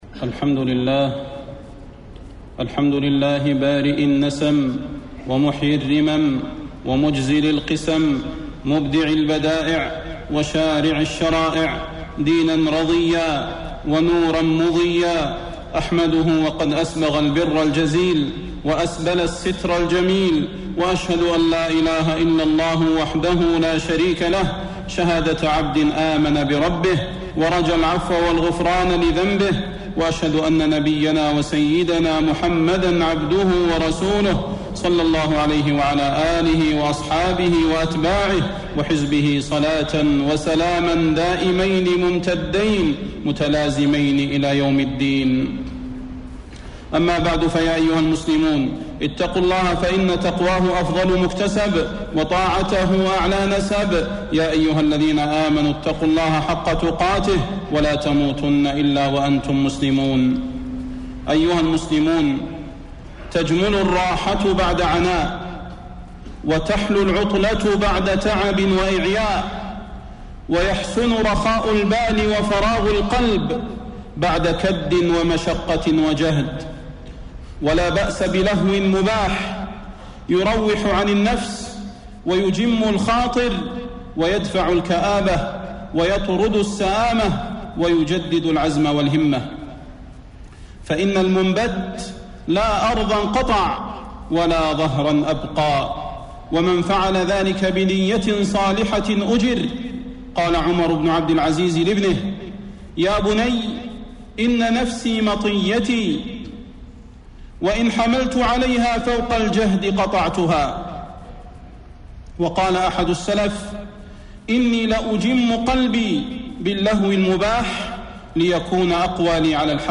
خطب الحرم المكي